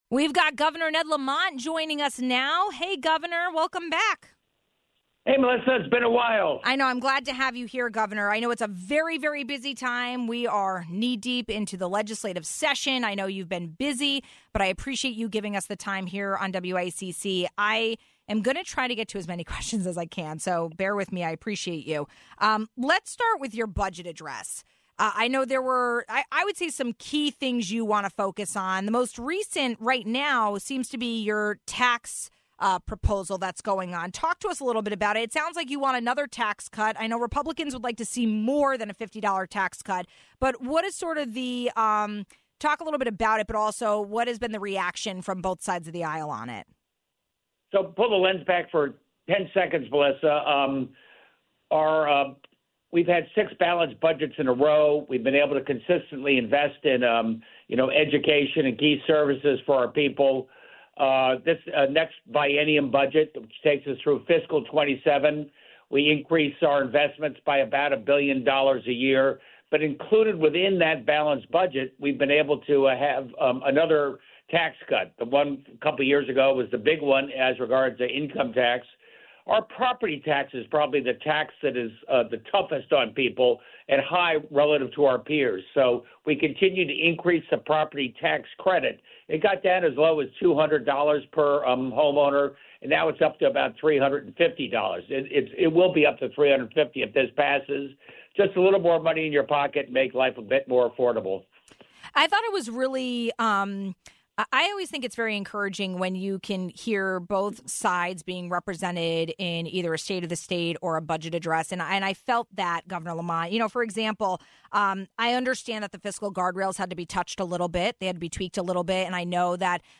We wanted to do a deeper dive into some of the items including proposed tax cuts, education funding and housing development. Governor Lamont joined the show with more.